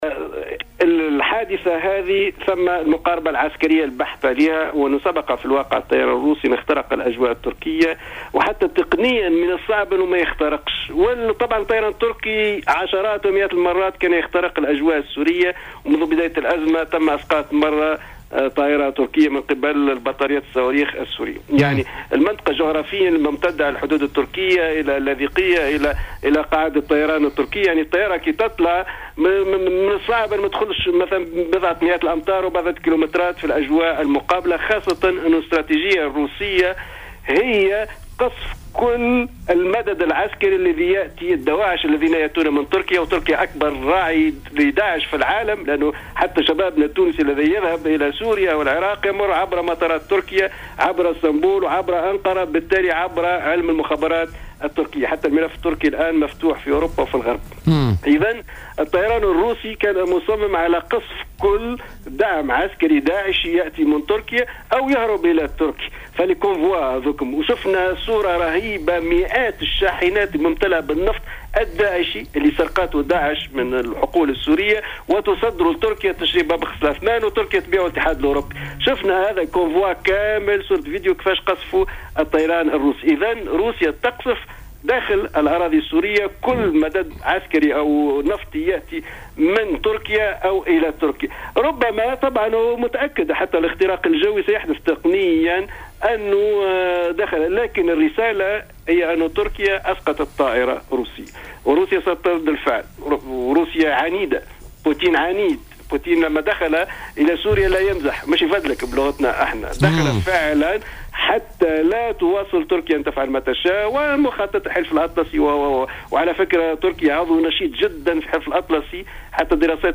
ورجح في المقابل في تصريح لـ"الجوهرة أف أم" زيادة التوتر بالمنطقة، متوقعا أن ترد روسي بالفعل واصفا الرئيس الروسي فلاديمير بوتن بـ "العنيد".